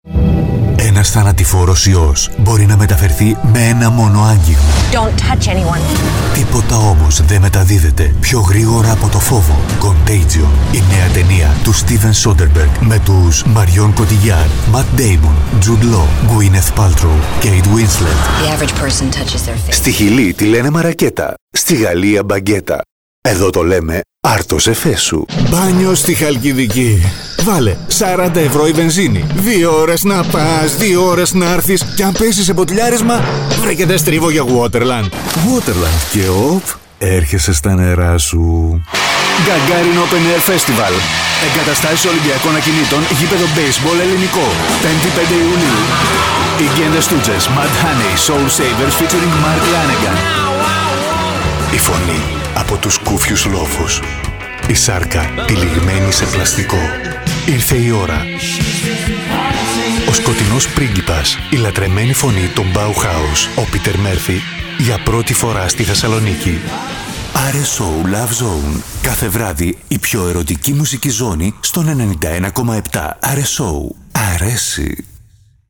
Greek Voice Over artist, radio presenter, TV production and copywriter
Sprechprobe: Werbung (Muttersprache):
AKG c214 M-Audio sound card